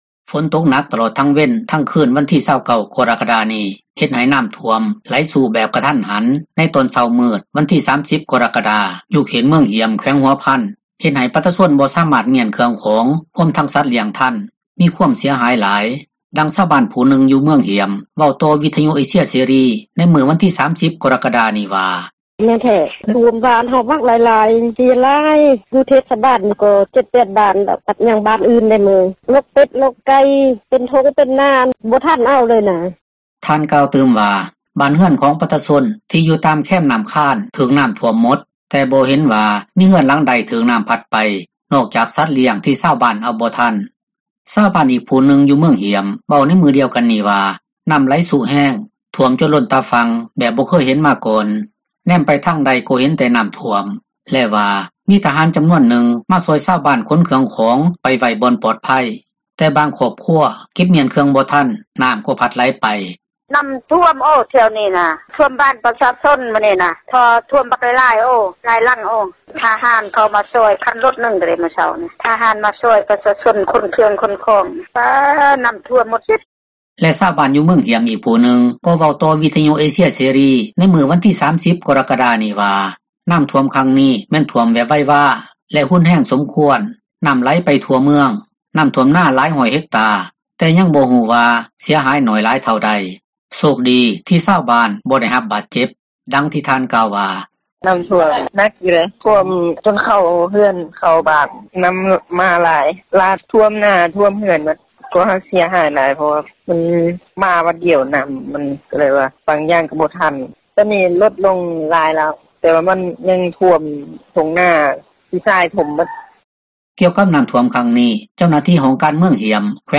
ເມືອງຮ້ຽມ ແຂວງຫົວພັນ ນໍ້າຖ້ວມ ກະທັນຫັນ — ຂ່າວລາວ ວິທຍຸເອເຊັຽເສຣີ ພາສາລາວ